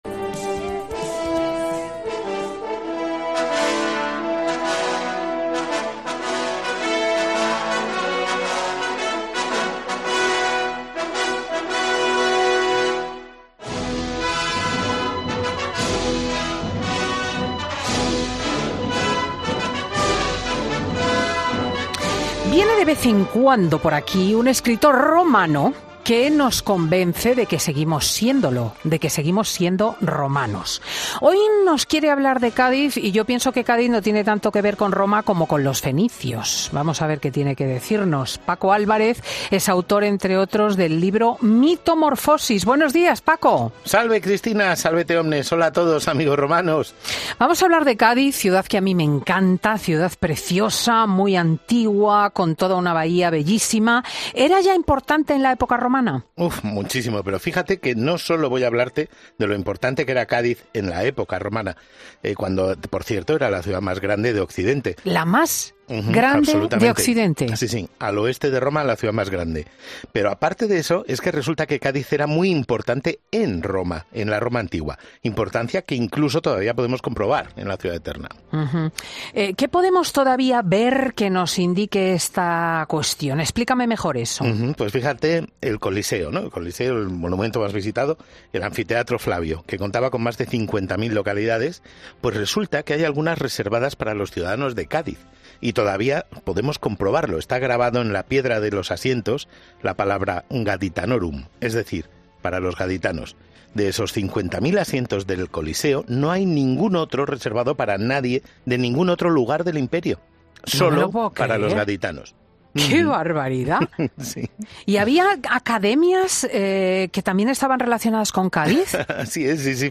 Viene de vez en cuando por Fin de Semana con Cristina un escritor romano que siempre nos cuenta cosas de la época romana.